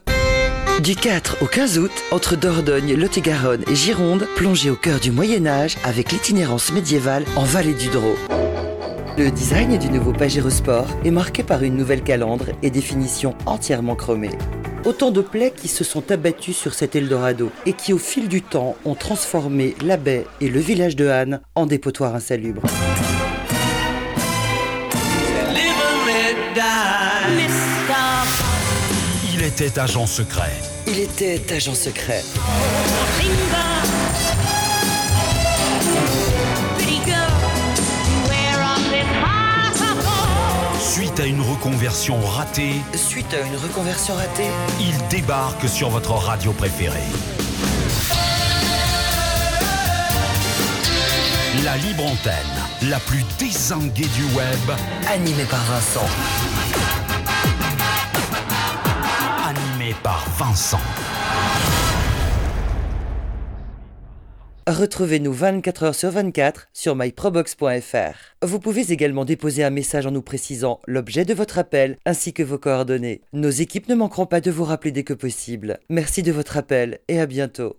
La voix, la voix qui prend peu de rides mais garde sa chaleur son empathie son enthousiasme !
Sprechprobe: Industrie (Muttersprache):